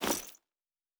Bag 12.wav